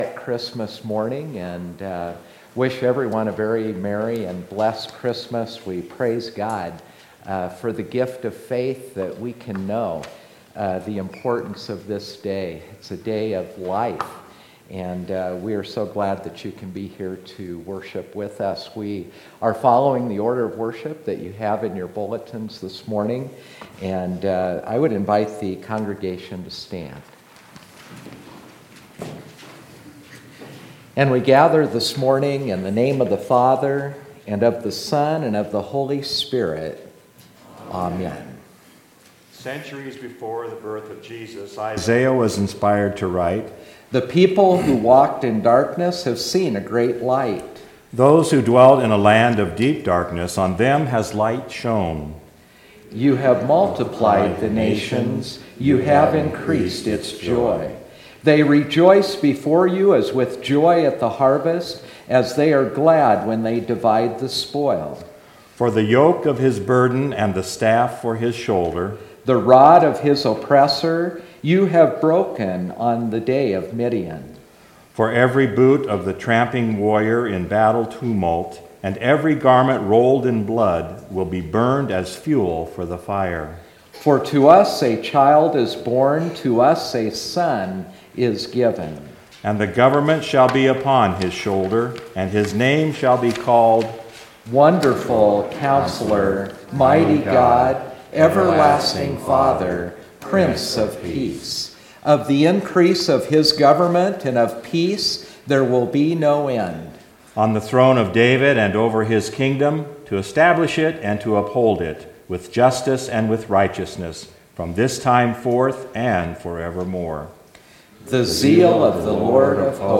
Chiristmas Morning Worship